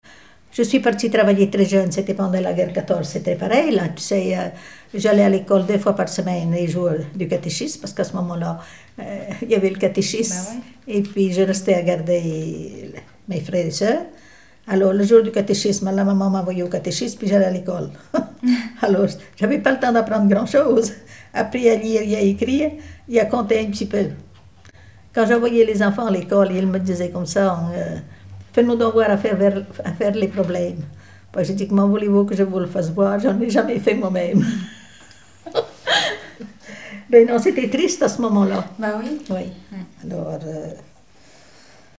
Témoignages sur les folklore enfantin
Catégorie Témoignage